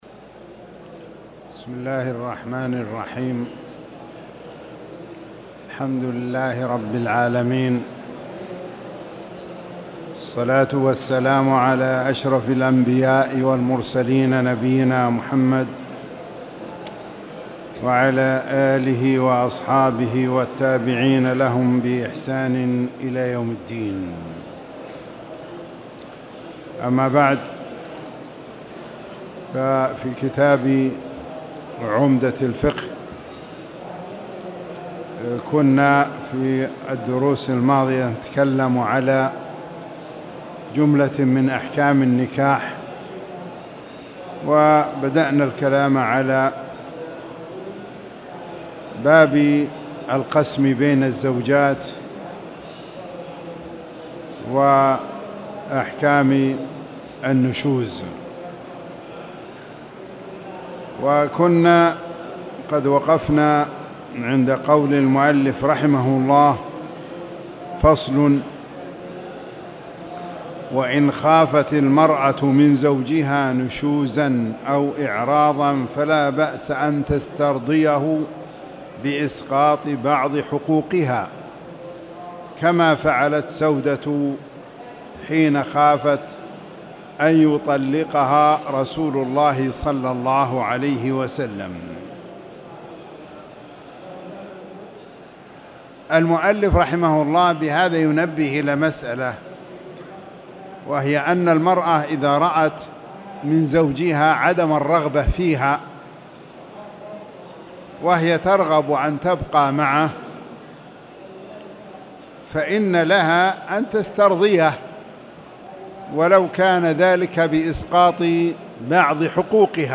تاريخ النشر ١٤ جمادى الأولى ١٤٣٨ هـ المكان: المسجد الحرام الشيخ